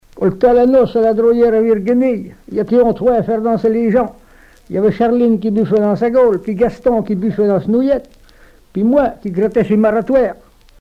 Genre formulette
Catégorie Récit